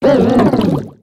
Grito de Jellicent.ogg
Grito_de_Jellicent.ogg.mp3